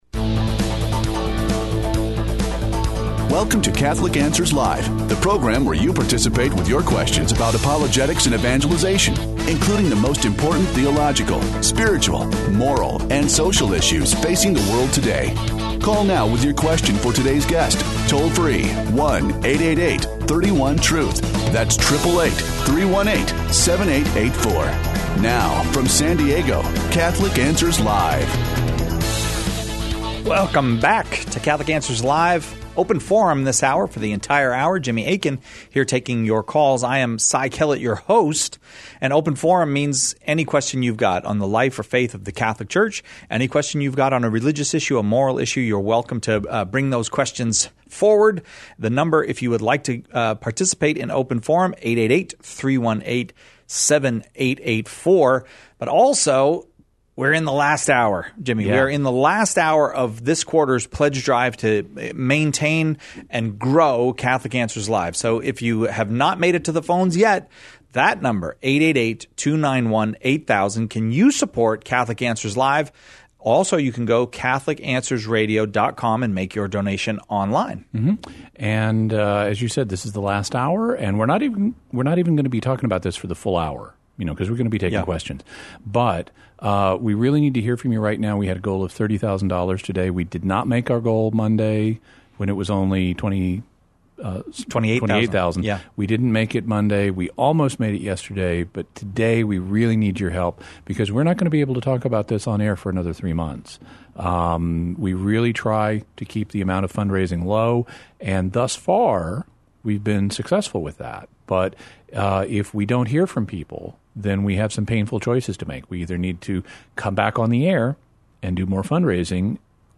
This hour concludes the on air portion of our quarterly pledge drive.